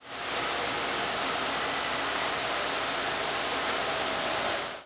American Tree Sparrow nocturnal
presumed American Tree Sparrow nocturnal flight calls
Nocturnal flight call sequence: 1. New York November 7, 1989 (WRE).